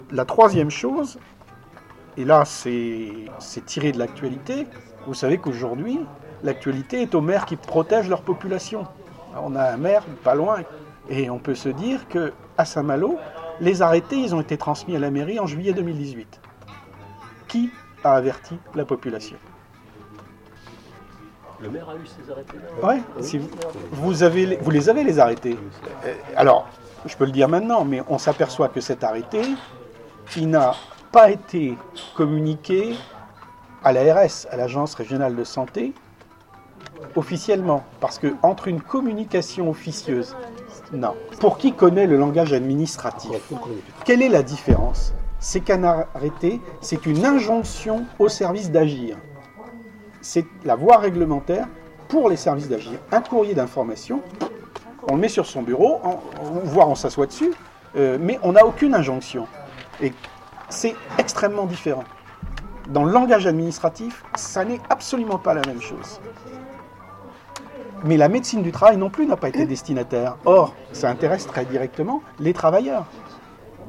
Pas plus que nous, la preuve, malgré le bruit de fond, nous vous proposons d’écouter les extraits de notre conférence de presse de début septembre 2019 :